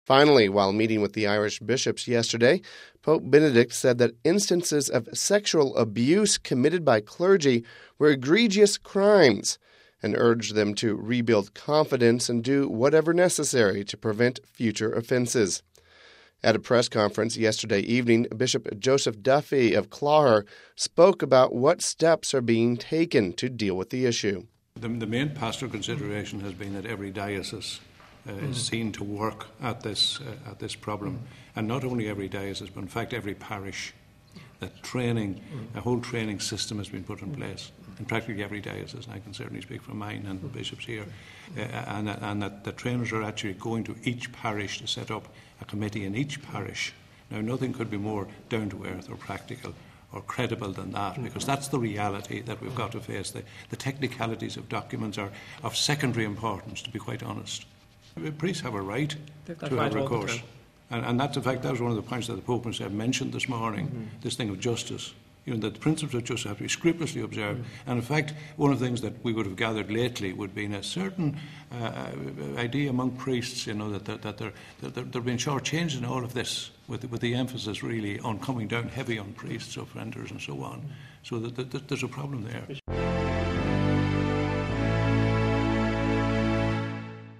Home Archivio 2006-10-29 17:49:31 Irish Bishops Talk About Pope's Address (29 Oct 06 - RV) The Irish Bishops held a press conference at the end of their ad limina visit to Rome. We have this report...